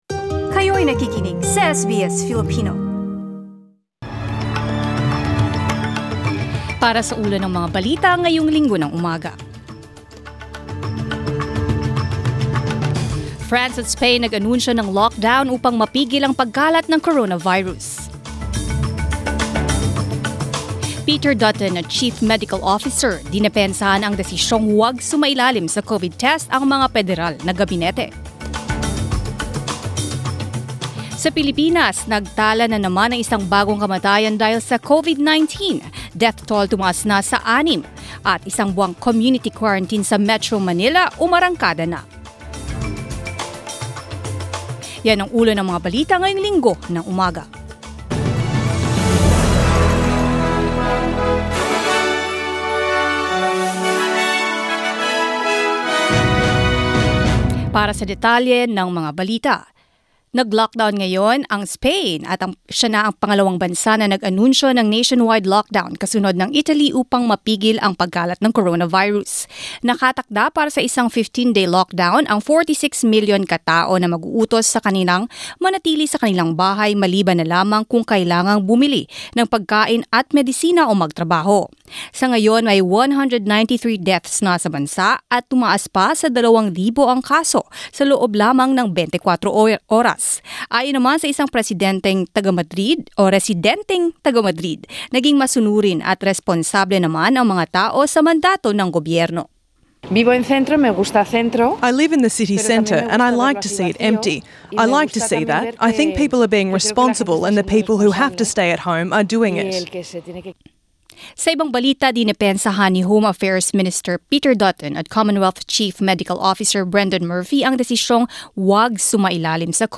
SBS News in Filipino, Sunday 15 March